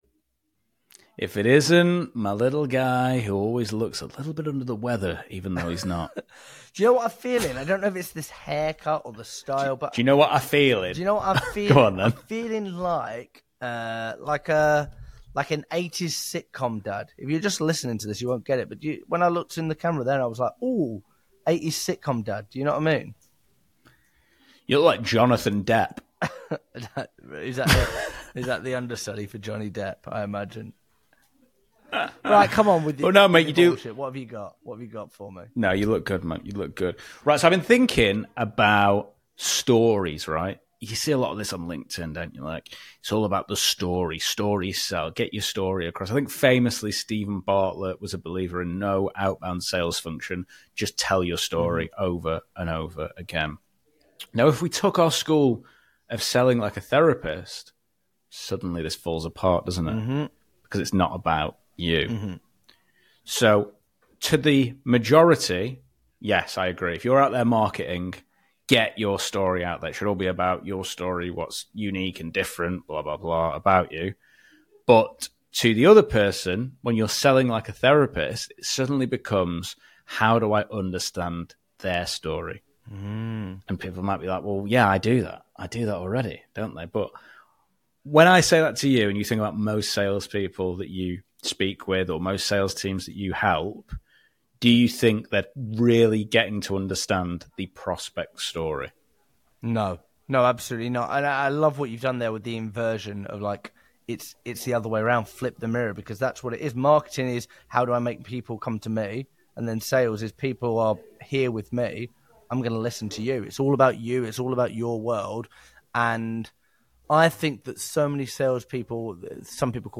Here’s what you’ll learn in the next 10 minutes:✅ Why the “just tell your story” trend is broken✅ The difference between marketing stories vs. sales conversations✅ How to flip the mirror and uncover your prospect’s story✅ A live cold-call role play showing depth over surface pitches✅ The psychology behind why recent examples matter (heuristics bias)✅ How to use story-driven questioning to find the real pain